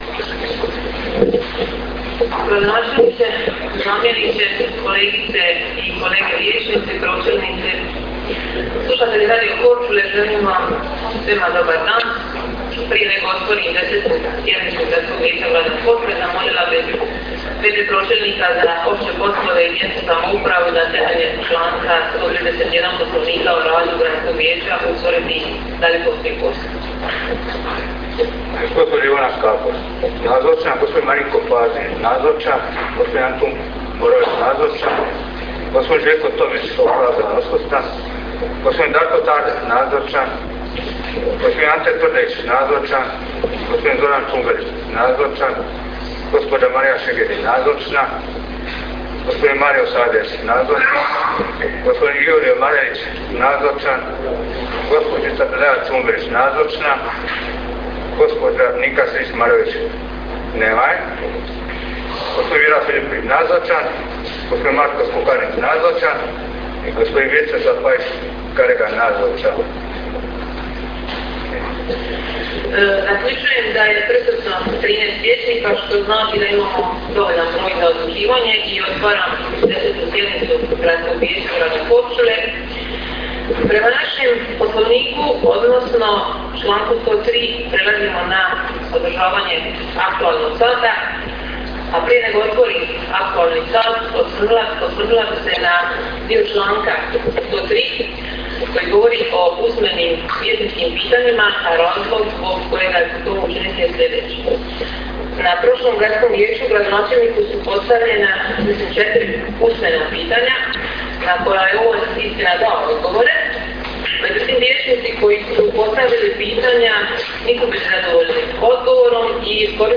Audio zapisnik sjednice možete preuzeti OVDJE.